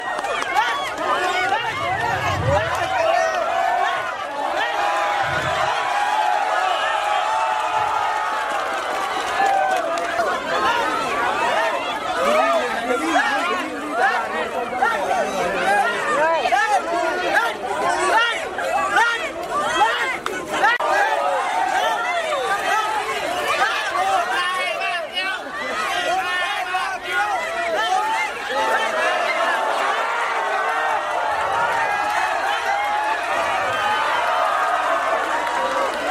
Âm thanh Kéo Co, hô hào, cỗ vũ…
Thể loại: Tiếng con người
Description: Tiếng reo hò, tiếng hò kéo co, tiếng cổ động, tiếng khích lệ, tiếng hô đồng đội, tiếng reo chiến thắng, reo vang và những lời khích lệ sôi nổi hòa cùng tiếng bước chân rầm rập, tiếng dây thừng căng kêu “cót két” khi hai đội dồn sức.
am-thanh-keo-co-ho-hao-co-vu-www_tiengdong_com.mp3